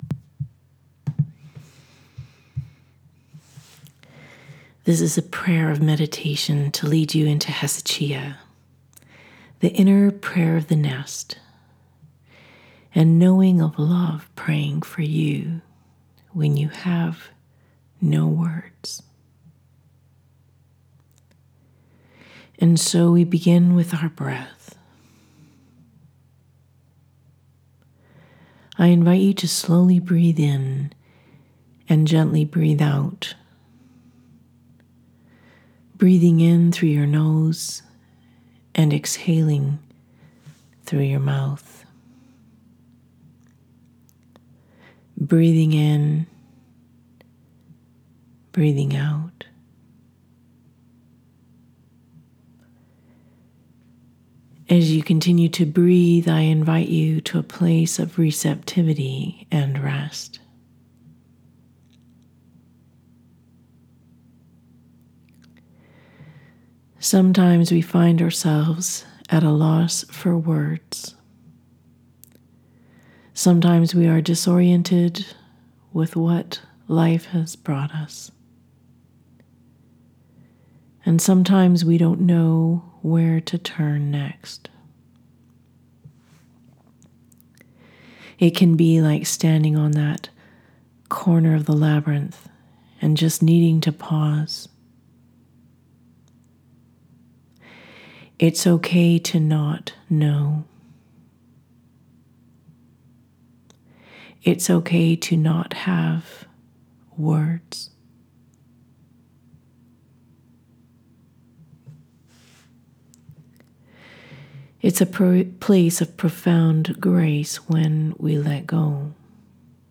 Meditation-Prayer-of-Hesychia-1.m4a